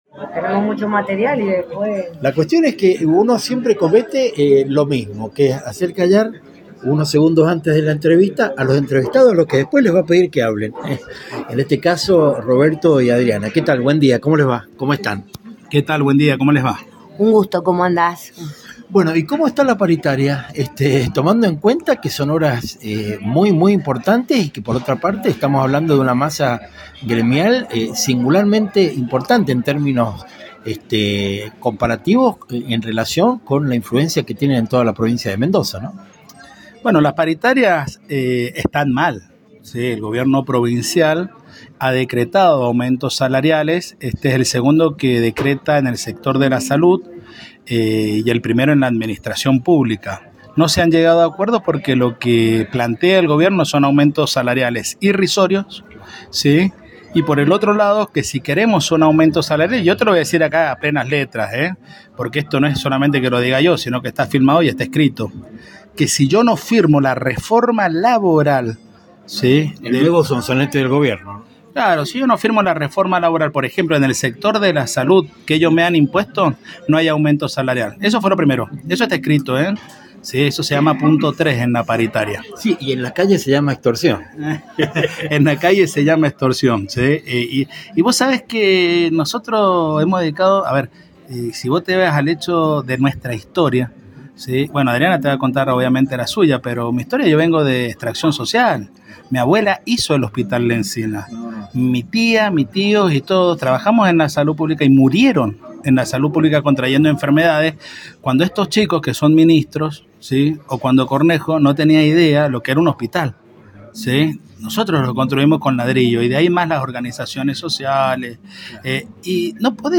Entrevistas militantes
Cada palabra una sentencia y sin pelos en la lengua.